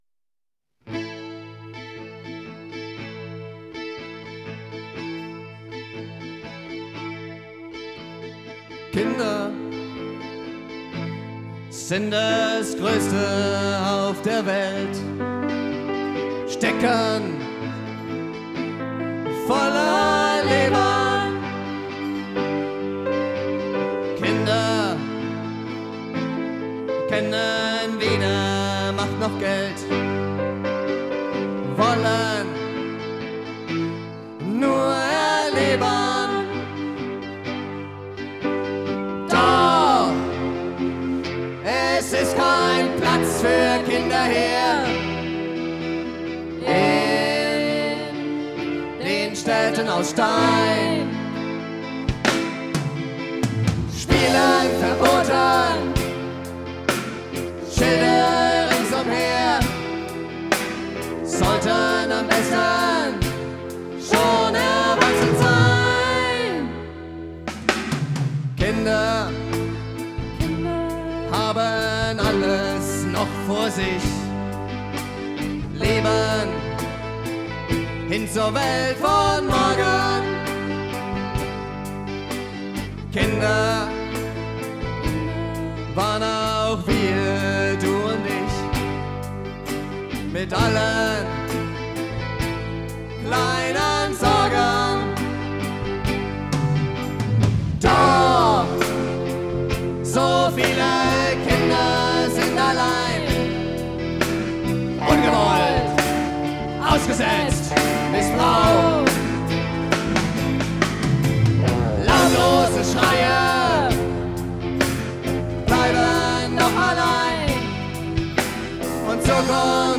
Audio (live; 5:29)Herunterladen